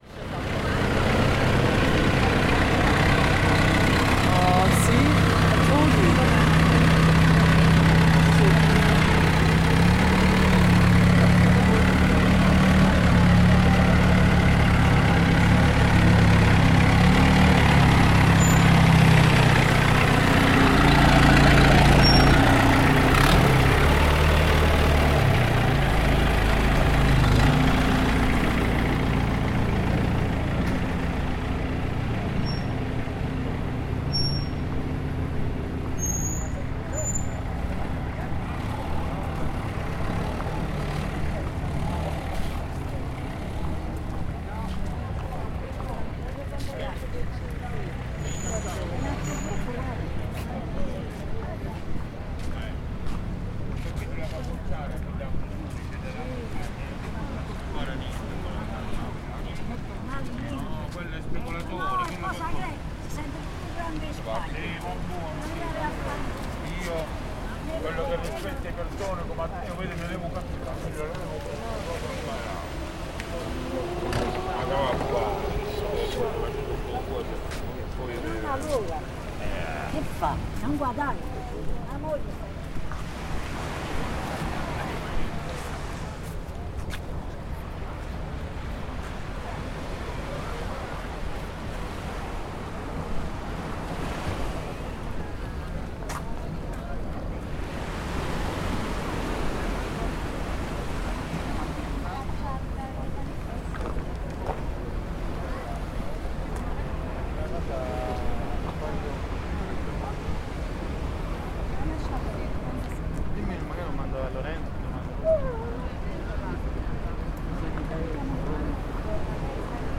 Container are unloaded, scooters drive past and night time walkers pass by in the small port on the island of Stromboli, off Sicily.